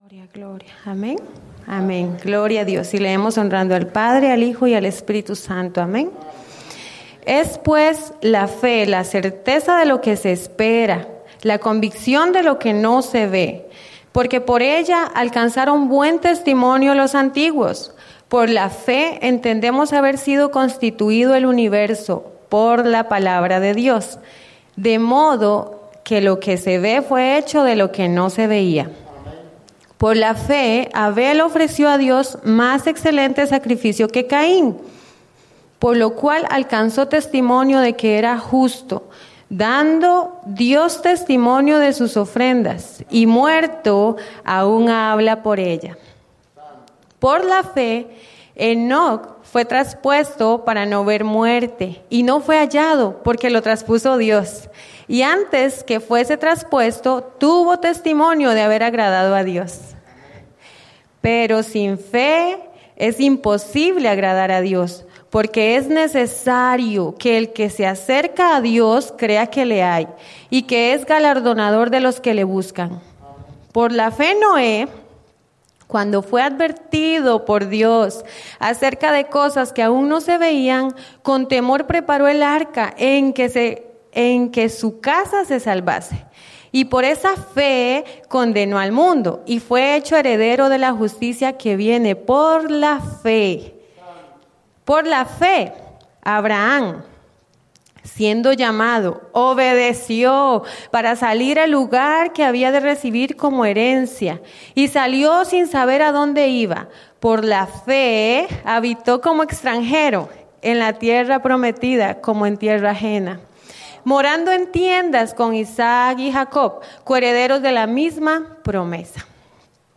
@ Souderton, PA